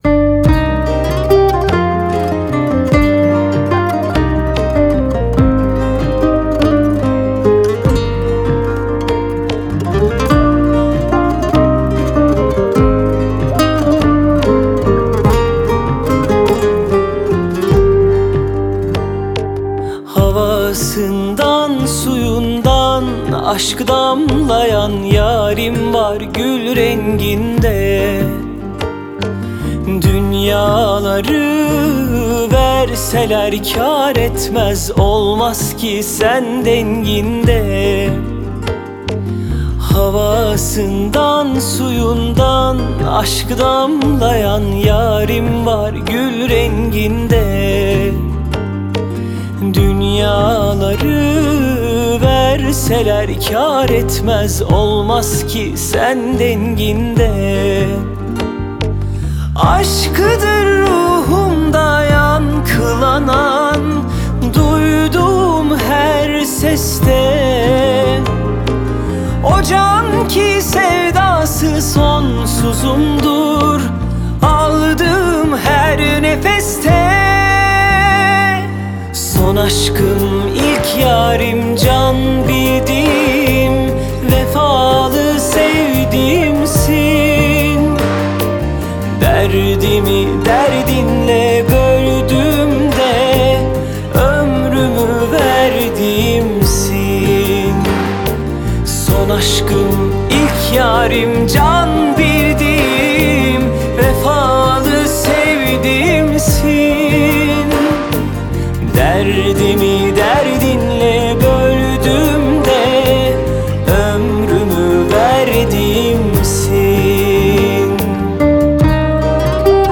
آهنگ ترکیه ای آهنگ شاد ترکیه ای آهنگ هیت ترکیه ای